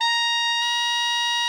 TENOR 39.wav